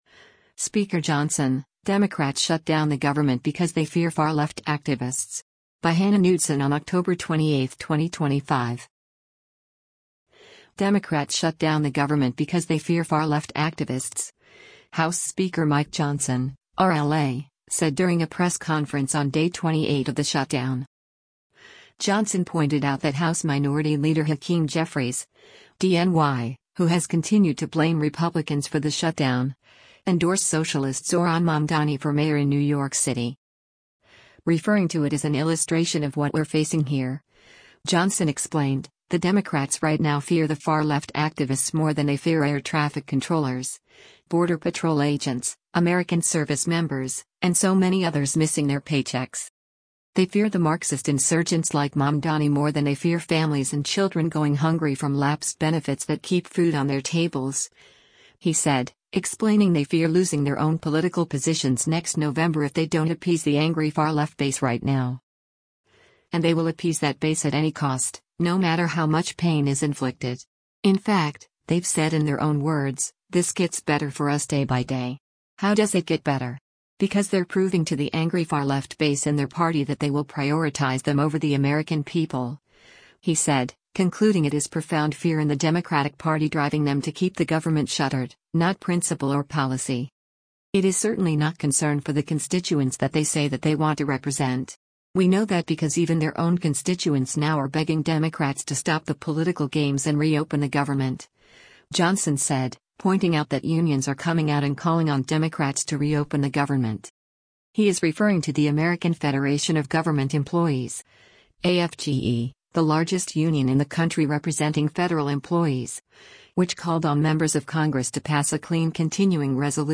Democrats shut down the government because they fear far-left activists, House Speaker Mike Johnson (R-LA) said during a press conference on day 28 of the shutdown.